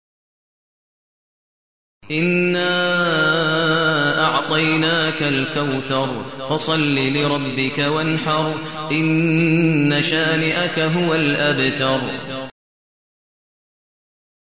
المكان: المسجد النبوي الشيخ: فضيلة الشيخ ماهر المعيقلي فضيلة الشيخ ماهر المعيقلي الكوثر